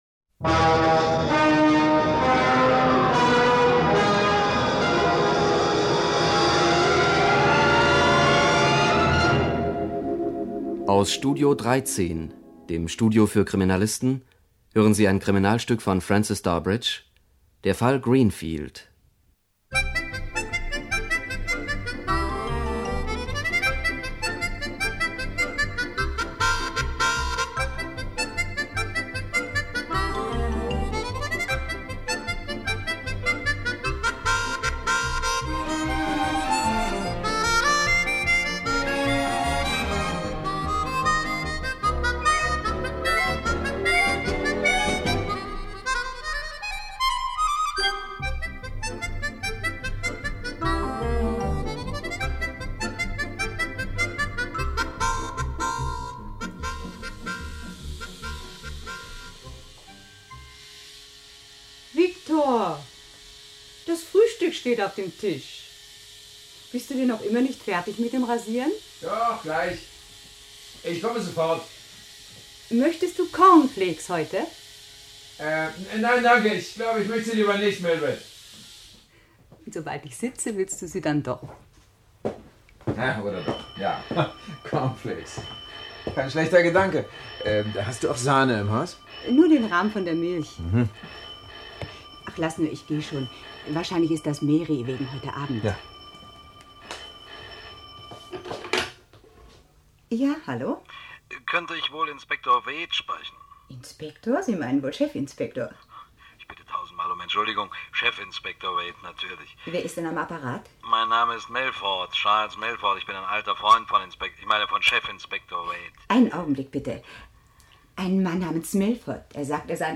Die Non-Paul-Temples Hörspiele mit Peter Fricke, Irm Hermann, Friedhelm Ptok u.v.a.
Peter Fricke , Irm Hermann , Friedhelm Ptok (Sprecher)